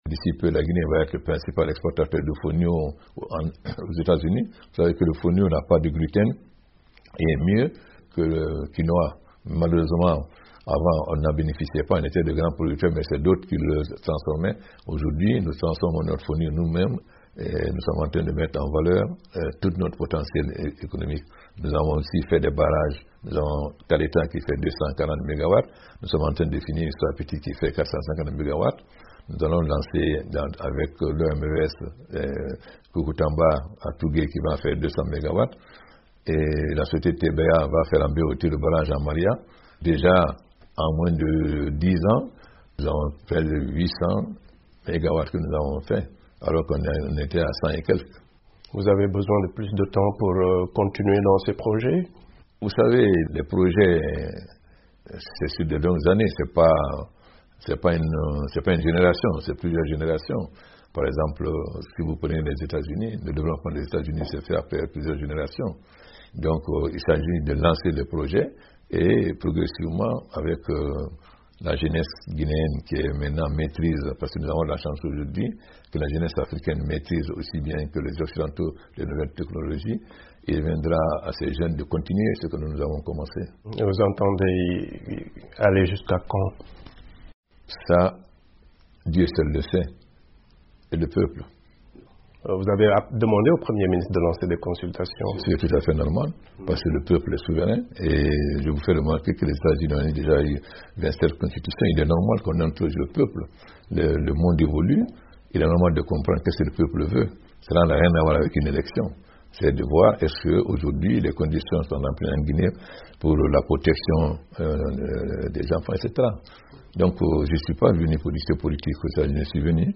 Dans un entretien exclusif à VOA Afrique, le président Alpha Condé évoque aussi les raisons de son récent appel à des consultations